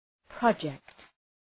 Προφορά
{prə’dʒekt}